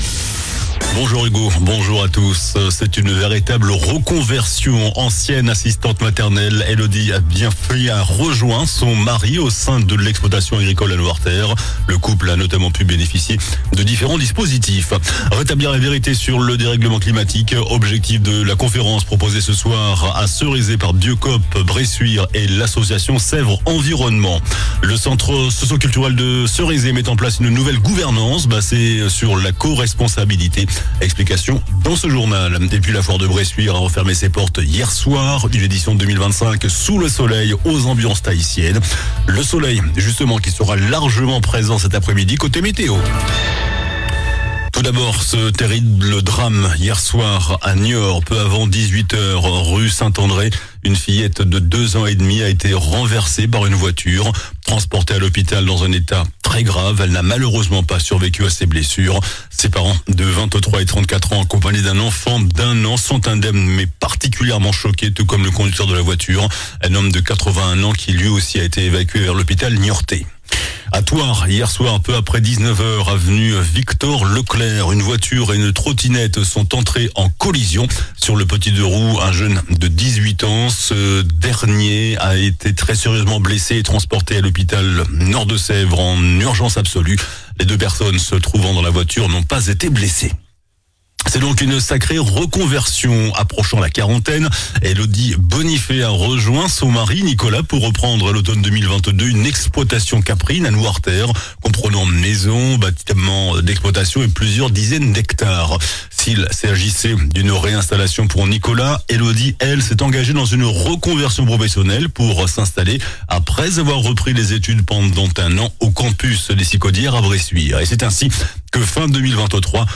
JOURNAL DU MARDI 08 AVRIL ( MIDI )